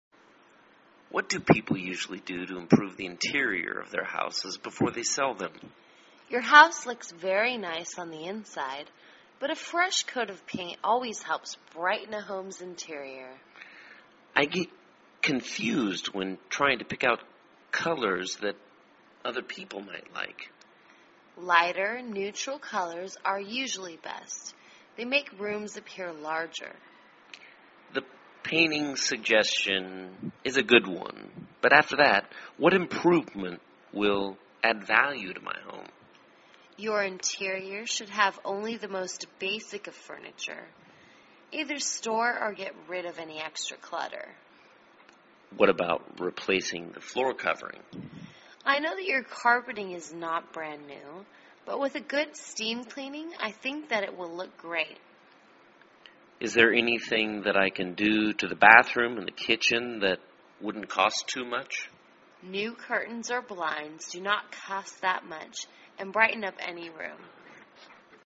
卖房英语对话-Fixing Up the Interior(3) 听力文件下载—在线英语听力室